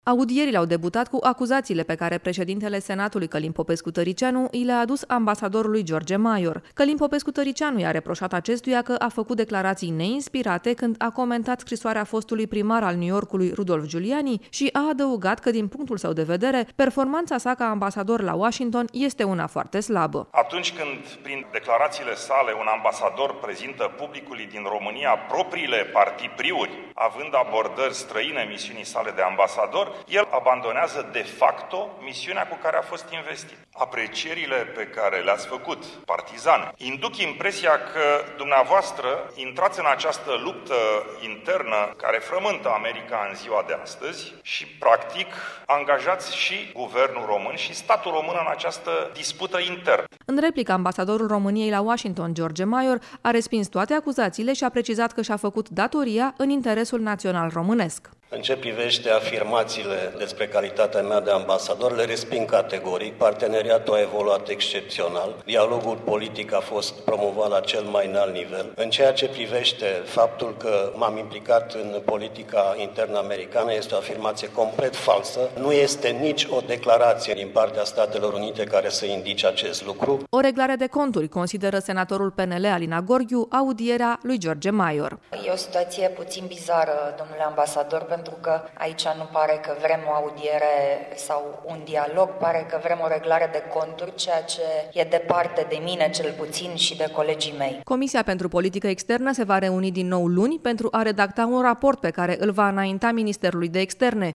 a urmărit audierile de astăzi din Comisia de politică externă a Senatului şi transmite: